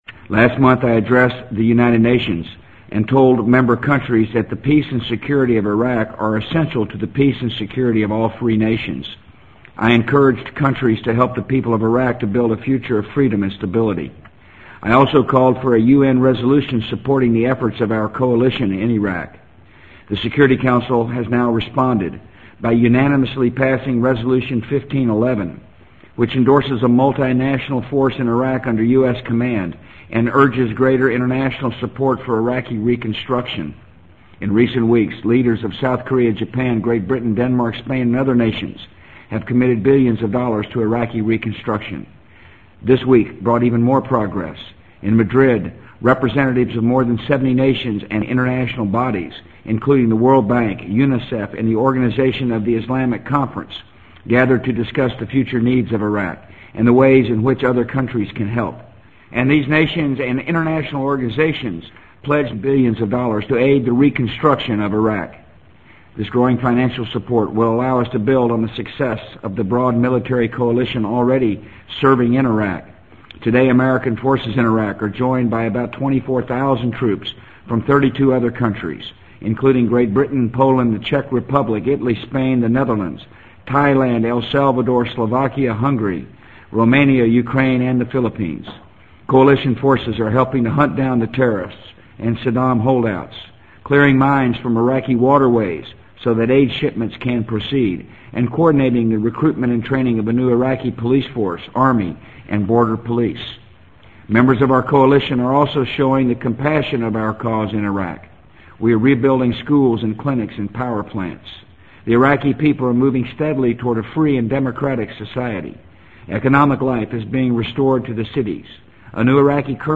【美国总统George W. Bush电台演讲】2003-10-25 听力文件下载—在线英语听力室